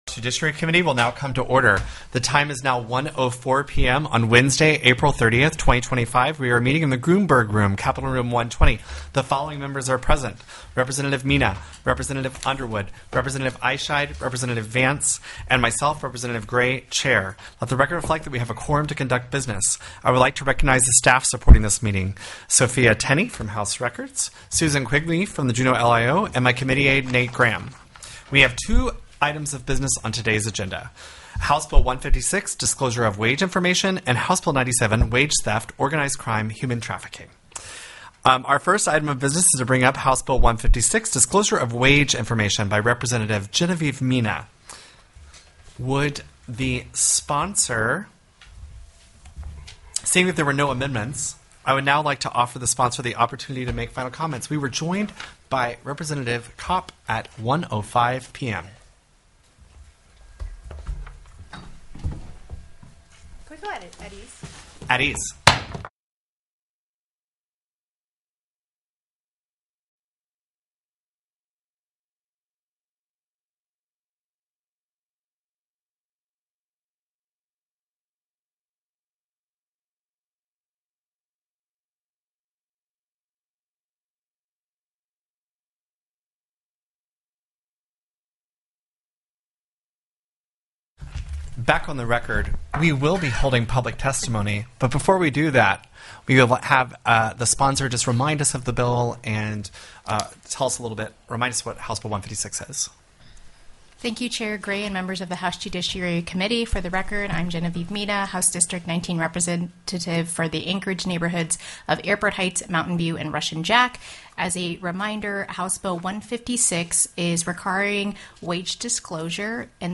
The audio recordings are captured by our records offices as the official record of the meeting and will have more accurate timestamps.
RETAIL THEFT/FUND;MKTPLACE SALES TAX TELECONFERENCED Moved CSHB 97(JUD) Out of Committee += HB 156 DISCLOSURE OF WAGE INFORMATION TELECONFERENCED Moved HB 156 Out of Committee pdf txt HB 97 -ORG.